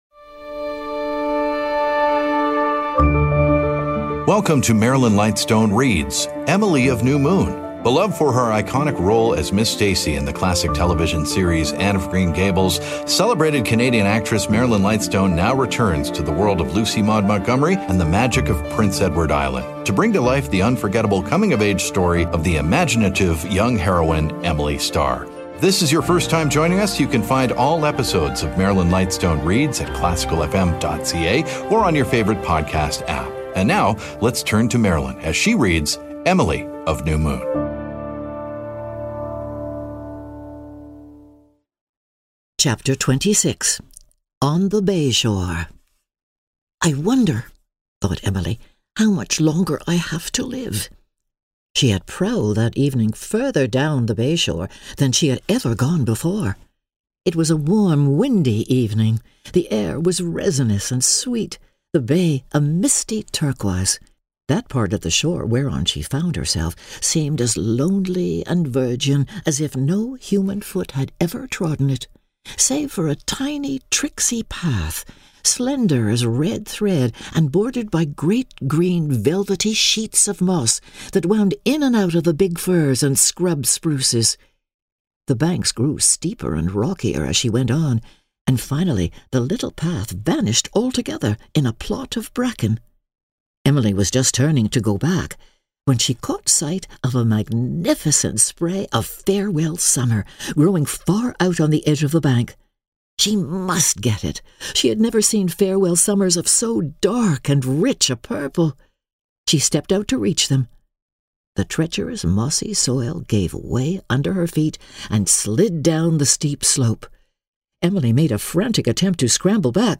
Known for her roles on stage and screen, as well as her daily radio program "Nocturne" on The New Classical FM, acclaimed actress Marilyn Lightstone now brings classic literature to life with dramatic readings.